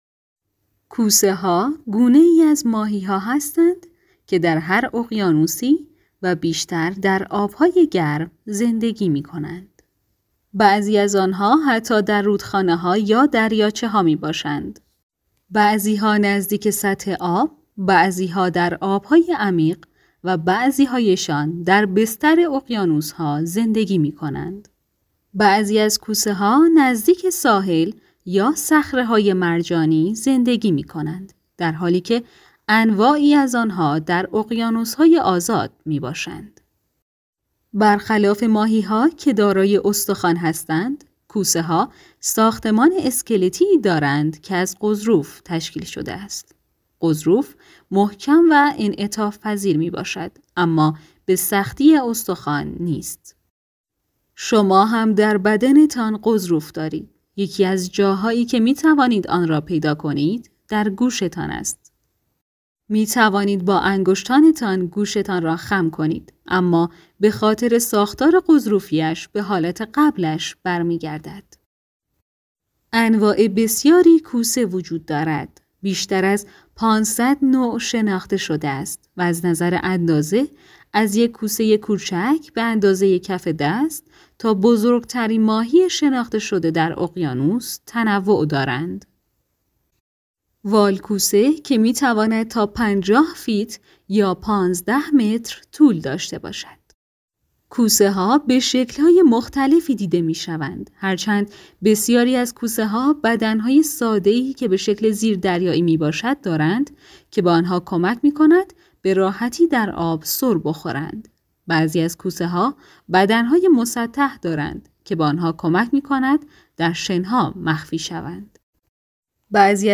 گوینده